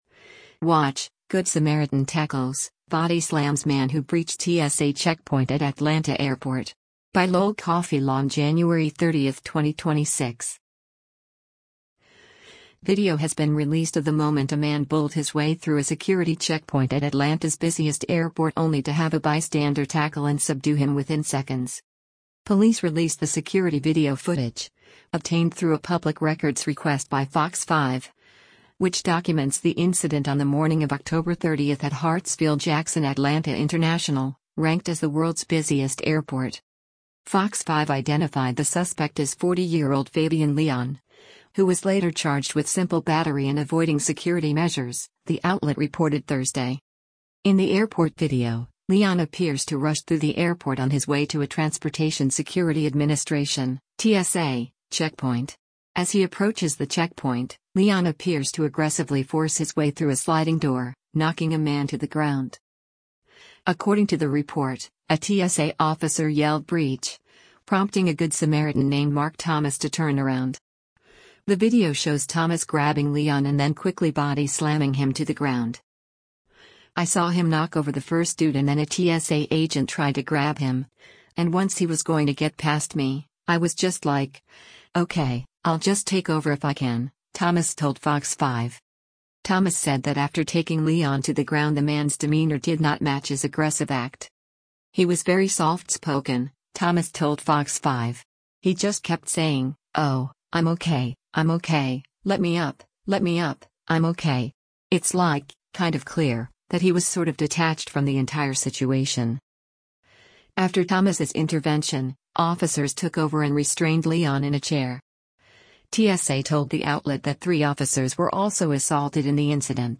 Video has been released of the moment a man bulled his way through a security checkpoint at Atlanta’s busiest airport only to have a bystander tackle and subdue him within seconds.